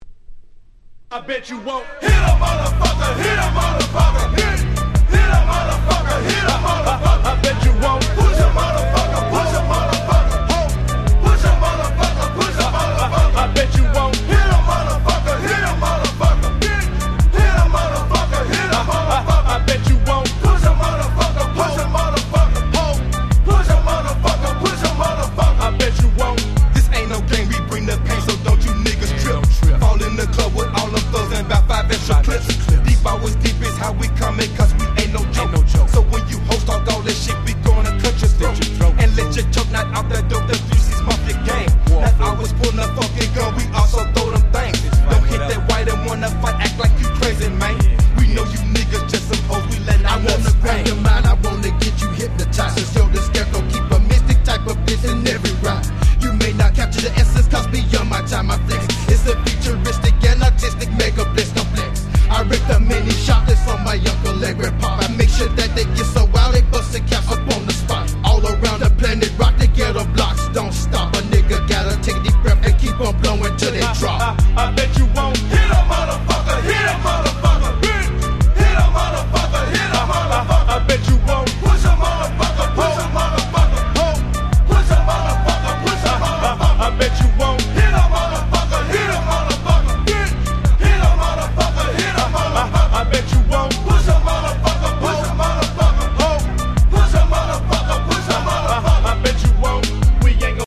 98' Smash Hit Southern Hip Hop !!